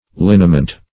Search Result for " linament" : The Collaborative International Dictionary of English v.0.48: Linament \Lin"a*ment\ (l[i^]n"[.a]*ment), n. [L. linamentum, fr. linum flax.]